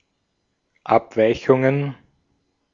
Ääntäminen
Ääntäminen Tuntematon aksentti: IPA: /ˈapˌvaɪ̯çʊŋən/ Haettu sana löytyi näillä lähdekielillä: saksa Käännöksiä ei löytynyt valitulle kohdekielelle. Abweichungen on sanan Abweichung taipunut muoto.